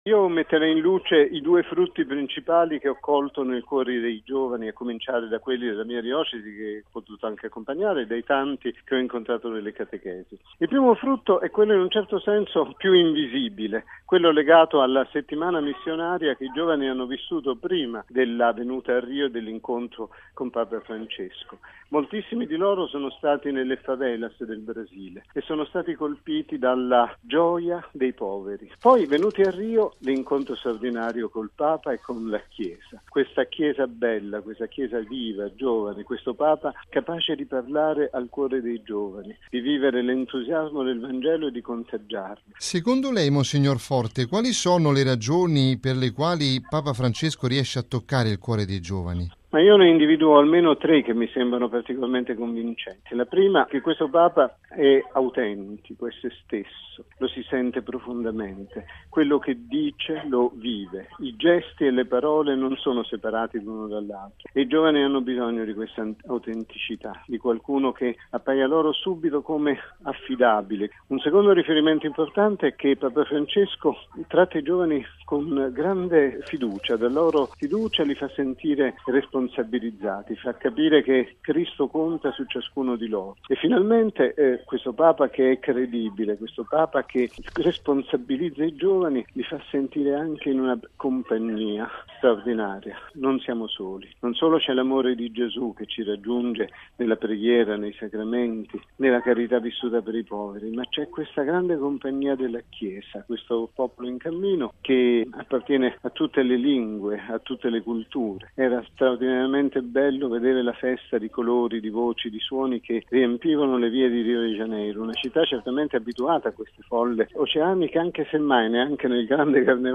Ma torniamo alla Gmg appena conclusa con un bilancio dell’arcivescovo di Chieti, mons. Bruno Forte.